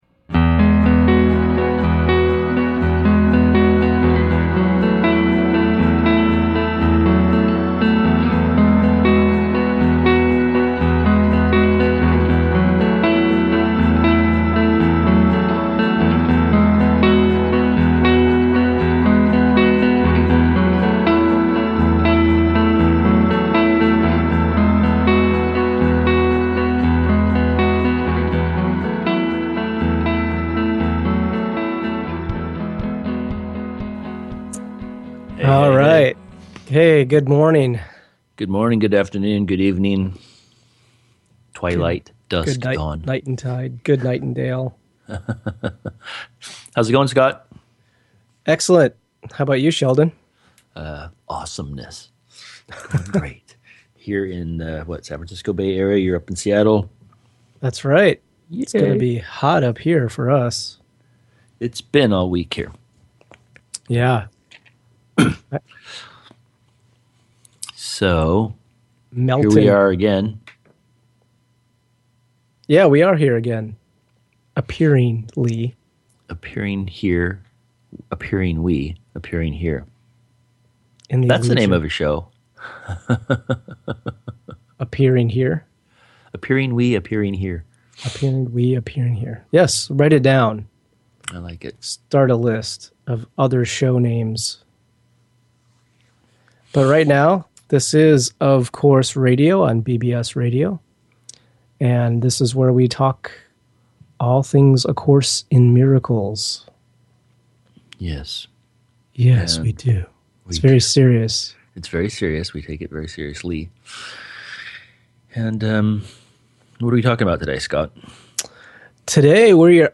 Of Course Radio is a lively broadcast focused on the messages within ‘A Course in Miracles’ as well as in-depth explorations into how we live forgiveness in our daily lives and remember our connection with God/Source.